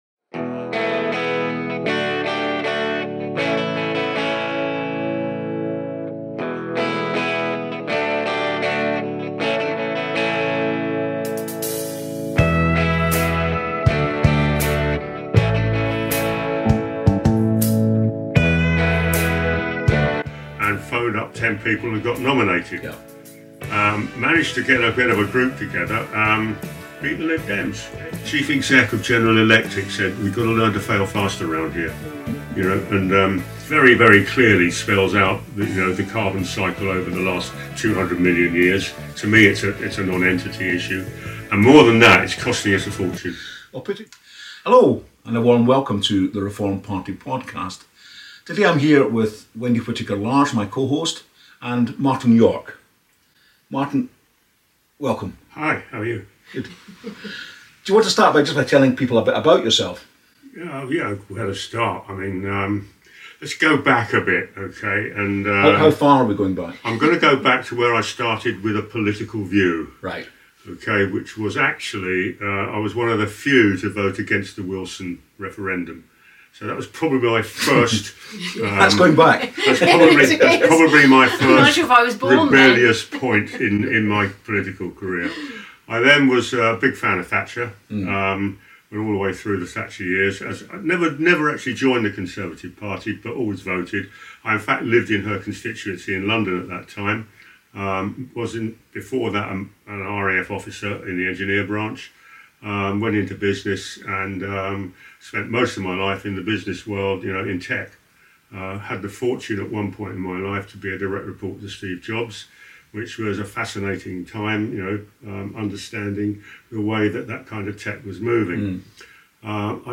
In this wide ranging conversation he highlights the challenges of running a branch and moves on to discuss issues around Net Zero and Carbon Capture and Storage.